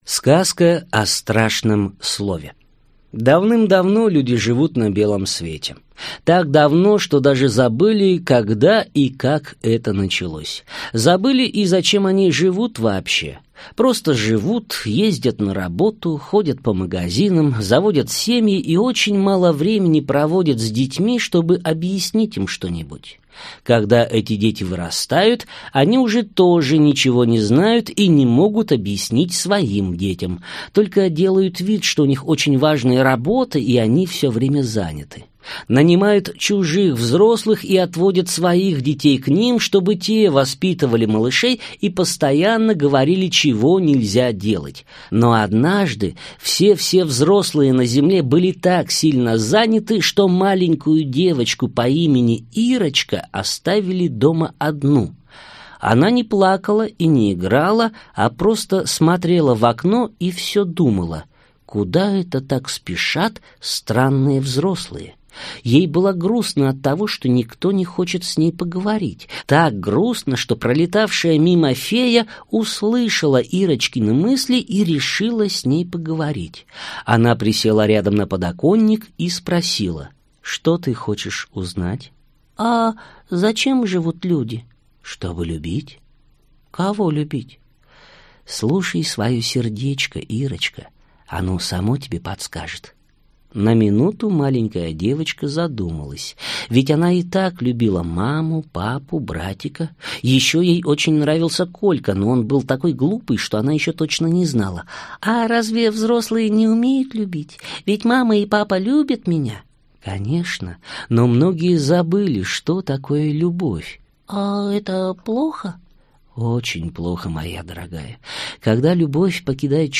Аудиокнига Белошвейка и белоручка (сборник) | Библиотека аудиокниг